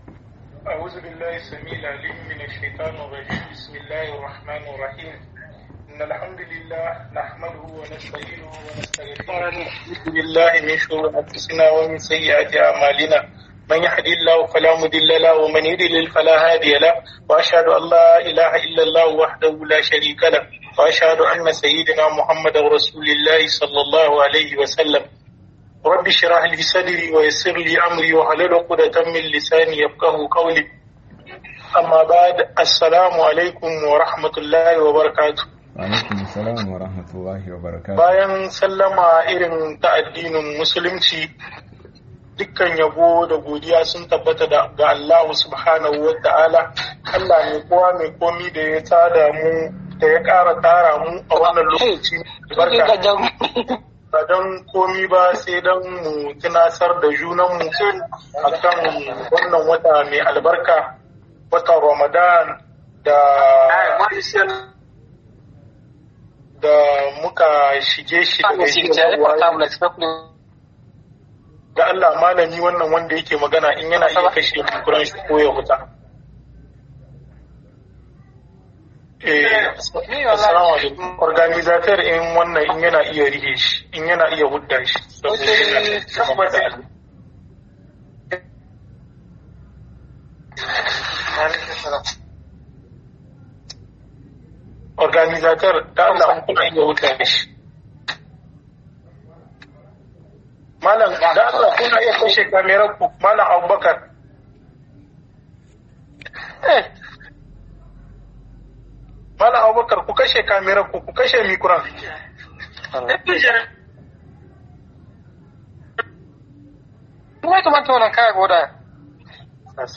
DABI'UN MAI AZUMI - Muhadara